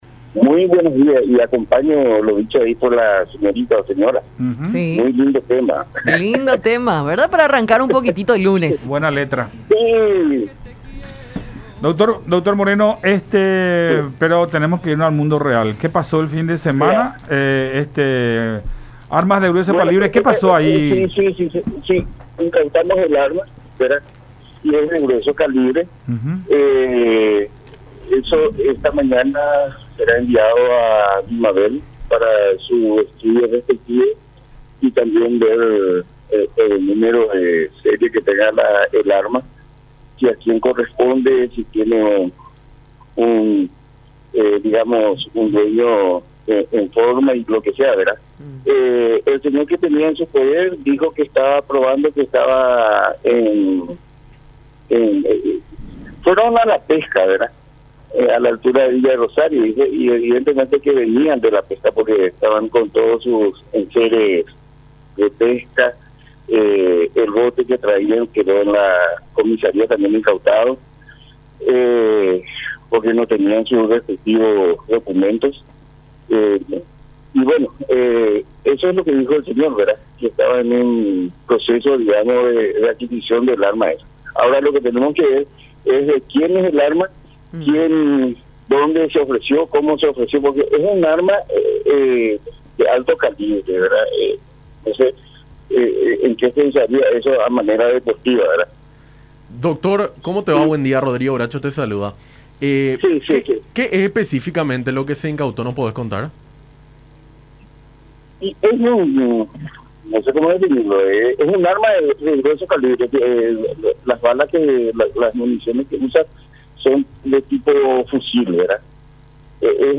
“Estas armas incautadas serán enviadas a la Dirección de Material Bélico (DIMABEL) para su análisis correspondiente, para saber a quién pertenece este lote”, aseveró el fiscal interviniente en el caso, Rubén Moreno, en conversación con Enfoque 800 por La Unión.